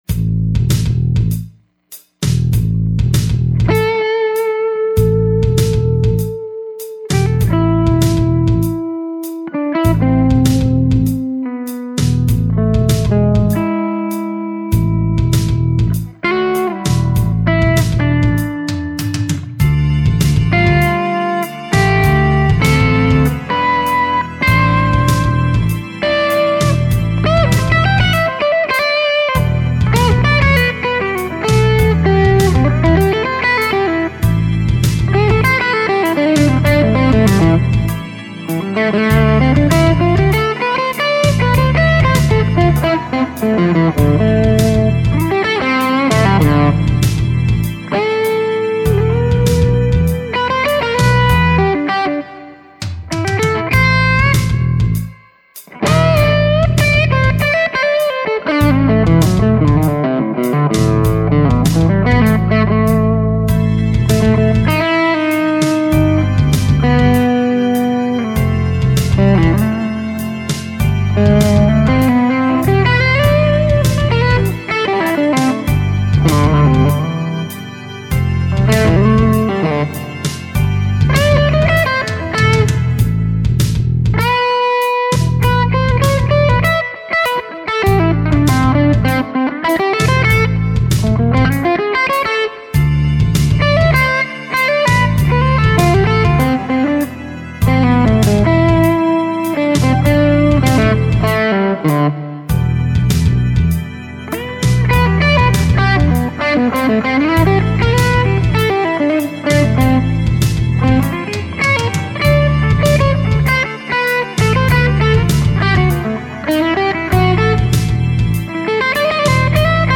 Sorry for crappy playing.